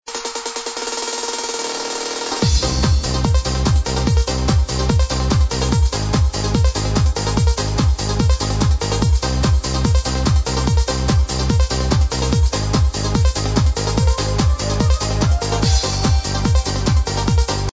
tune ID please - sounds very euro